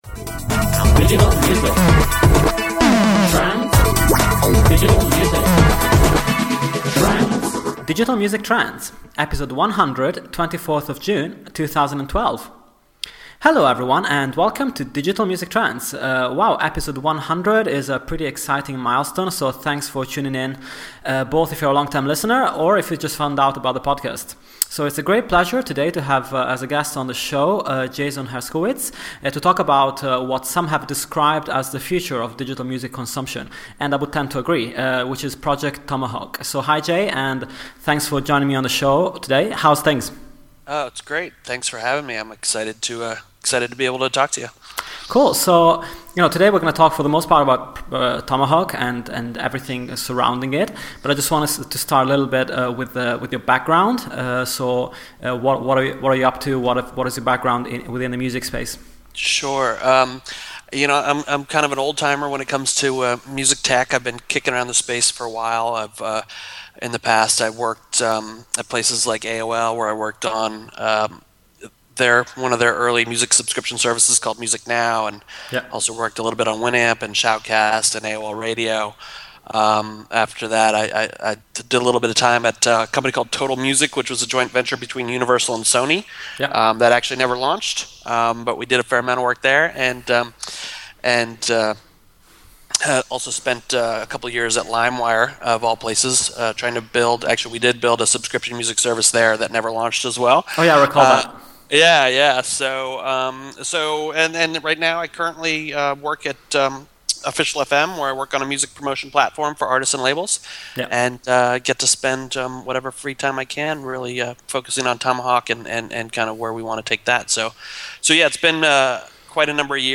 This week an interview